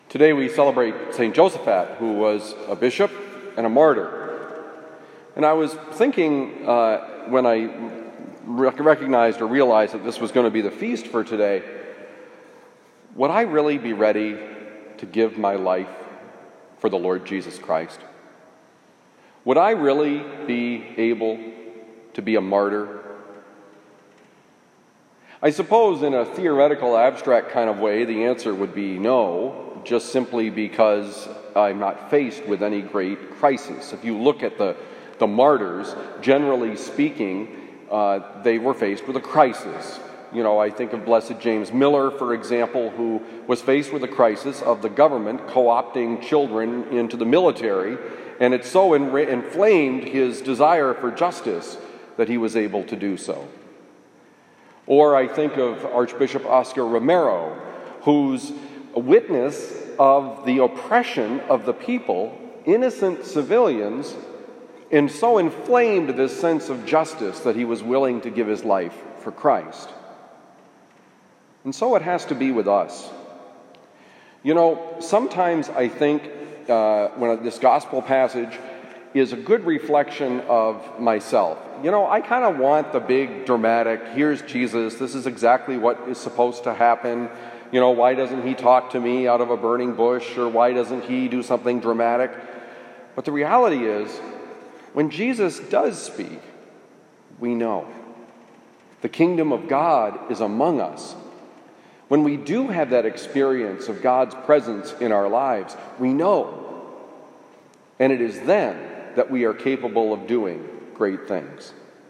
Homily for November 12, 2020
Homily given at Christian Brothers College High School, Town and Country, Missouri.